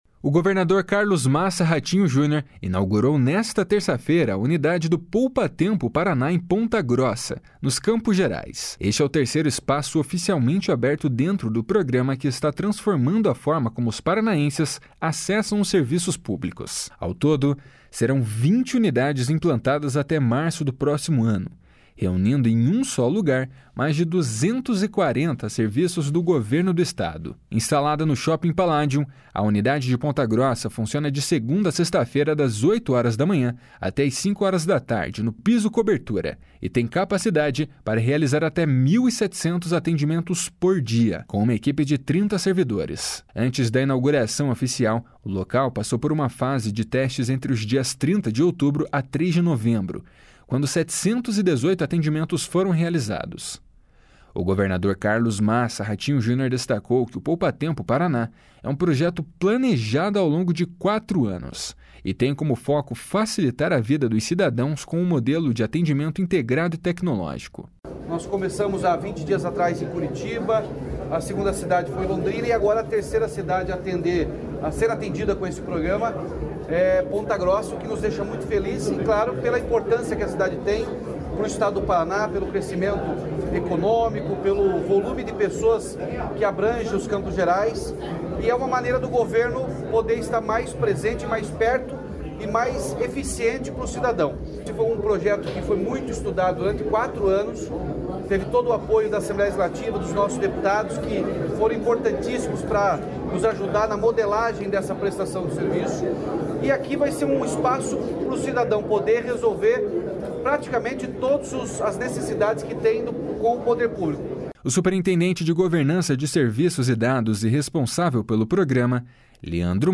// SONORA RATINHO JUNIOR //